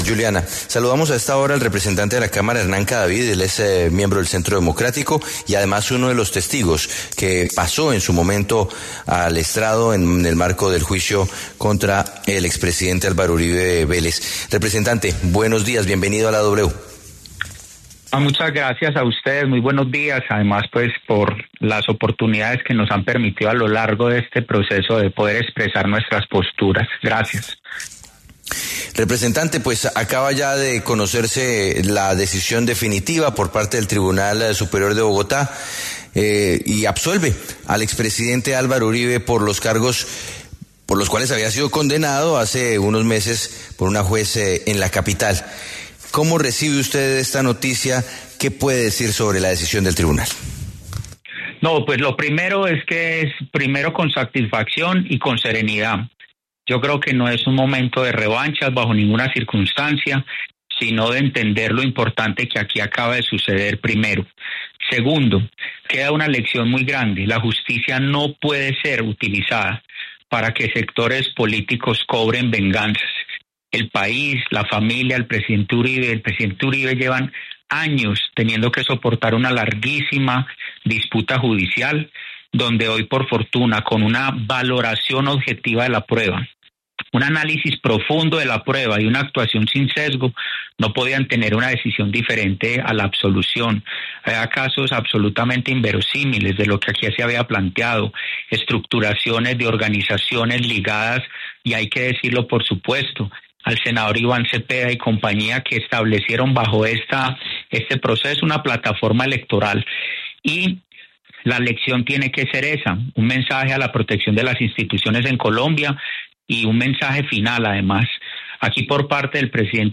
El congresista Hernán Cadavid, testigo en un caso judicial relacionado con Álvaro Uribe Vélez, conversó con La W tras la decisión de absolución al expresidente por parte del Tribunal Superior de Bogotá.